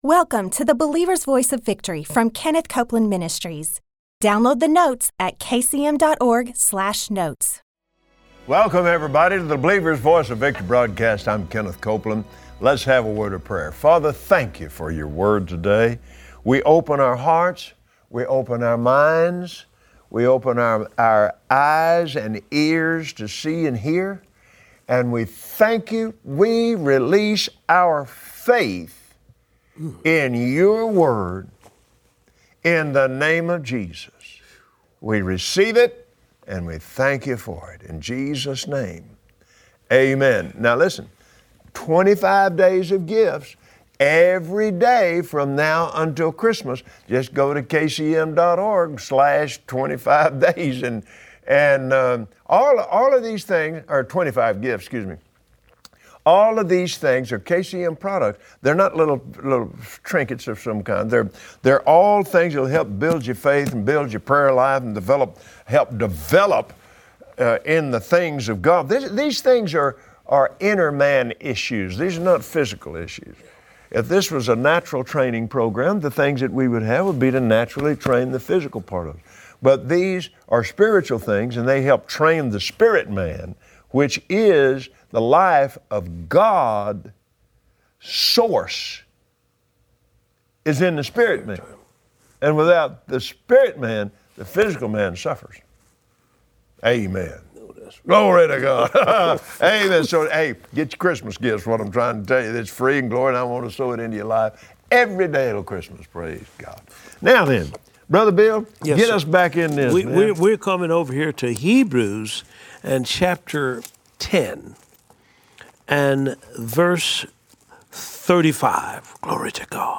Audio Broadcast